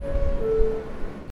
NYC_Subway, ding dong door opening sound
ding dong door nyc opening subway sound effect free sound royalty free Sound Effects